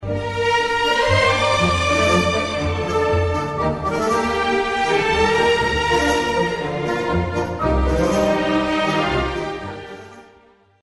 Tango